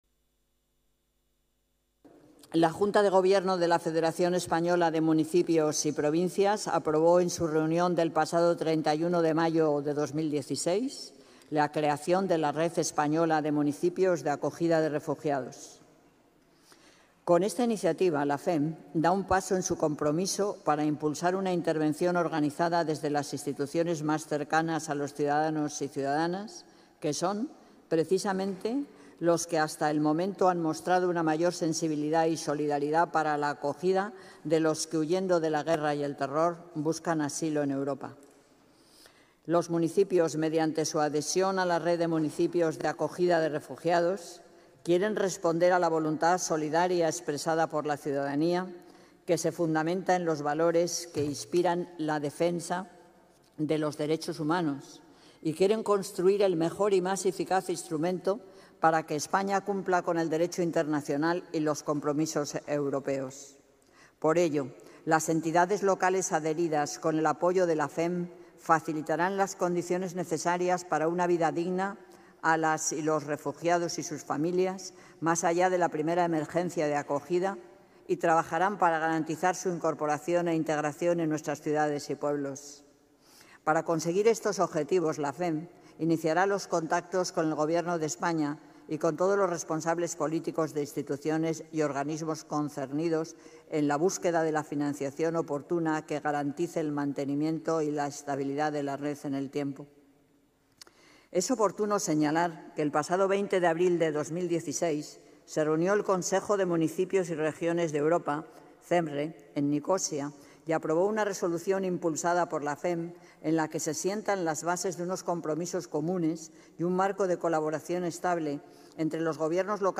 La declaración institucional a esta adhesión ha sido aprobada hoy en el pleno por unanimidad de todos los grupos políticos
DeclaracionInstitucionalDeAdhesionALaRedEspañolaDeMunicipiosDeAcogidaDeRefugiados-28-09.mp3